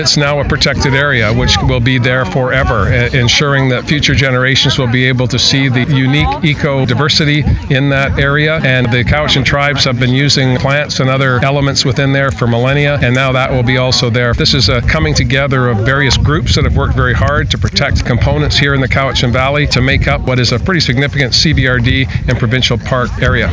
The Premier was in Shawnigan Lake earlier today to make an announcement.
Protecting the Eagle Heights area cost the province more than seven million dollars and Horgan is thankful to the groups who partnered with the province in this endeavour, including Cowichan Tribes.